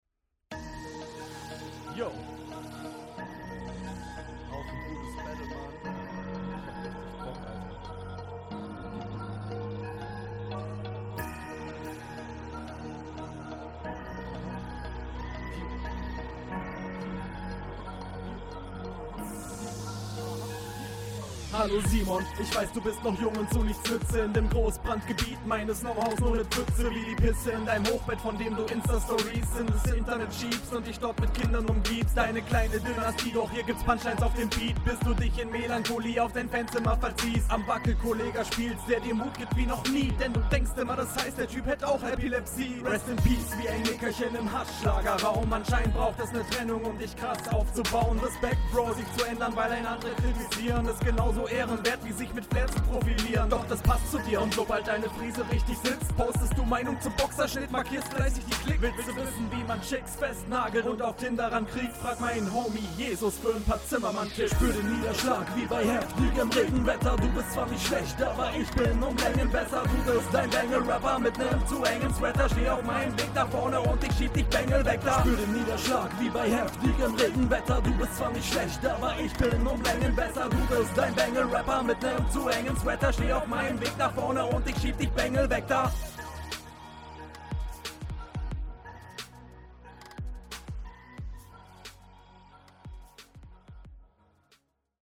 Ich finde dein Soundbild ist ausbaufähig.
Das erste was auffällt - Bro was bist du so leise Junge!